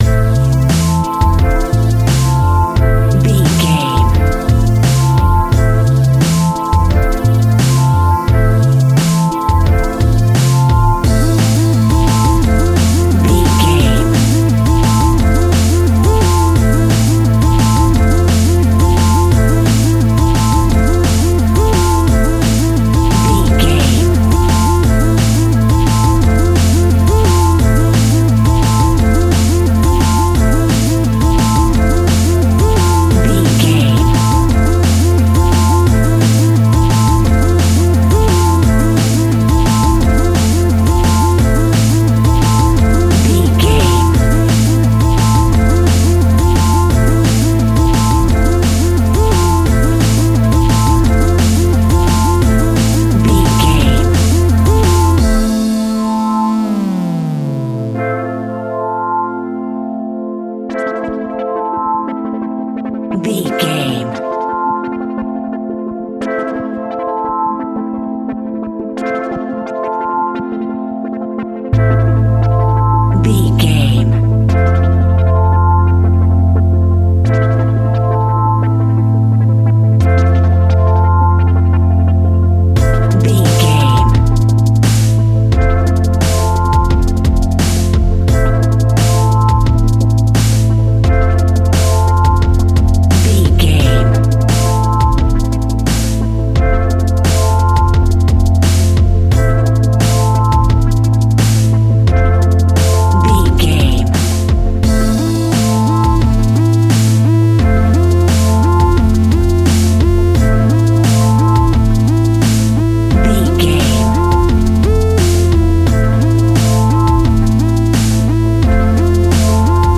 Aeolian/Minor
Fast
futuristic
frantic
driving
energetic
dramatic
groovy
Drum and bass
electronic
synth bass
synth lead
synth drums
synth pad
robotic